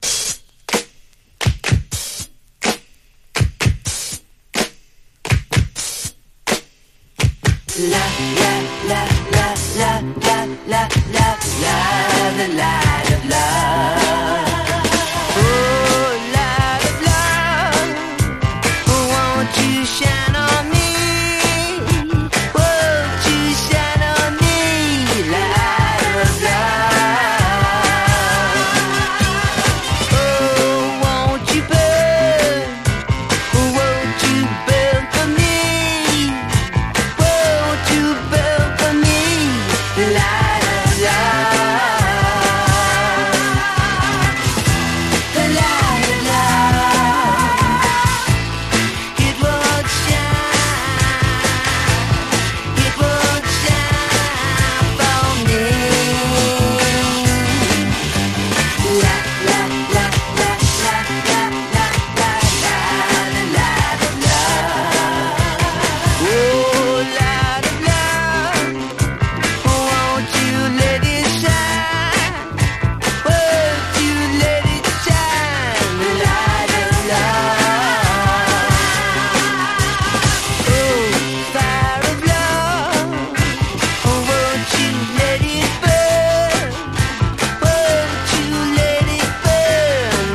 ハンド・クラップ＋ドラムが印象的なグラム・ロック・クラシック